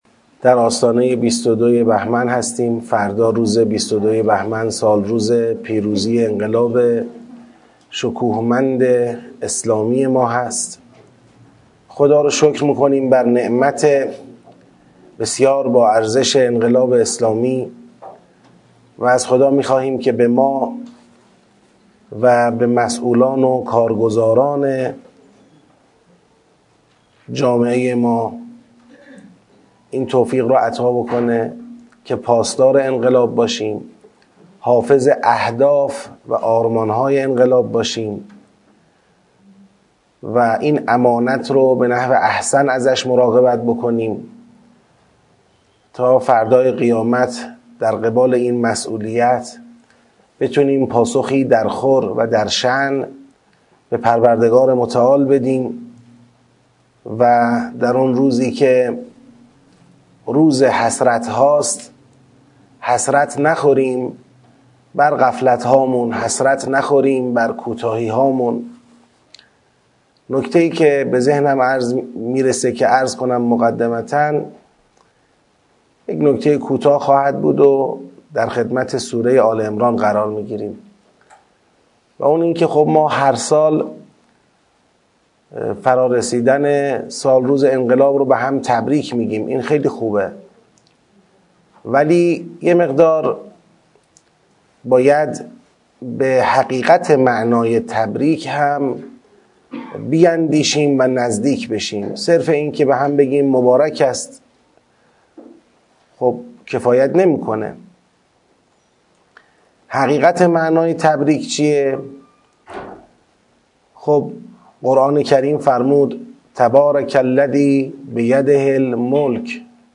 این سخنرانی در آغاز جلسۀ چهارم تدبر در سورۀ آل‌عمران بیان شده است.